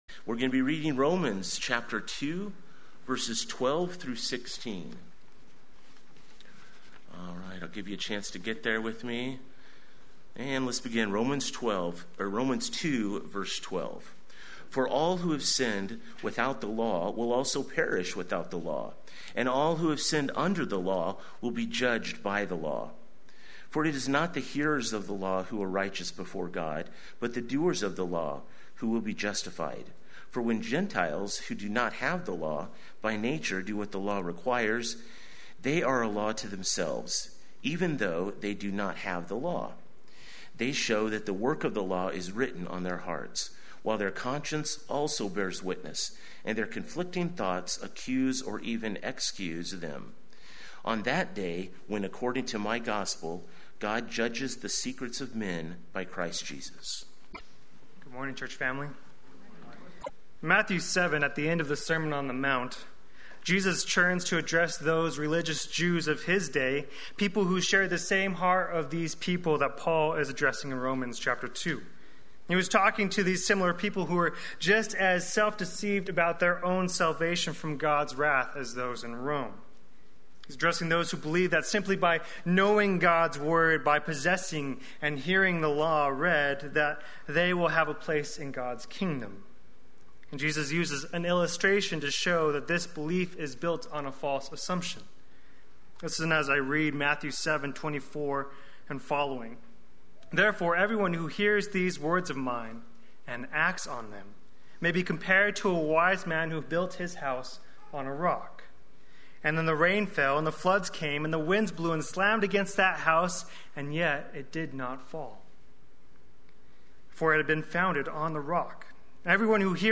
Play Sermon Get HCF Teaching Automatically.
The False Talisman of the Torah Sunday Worship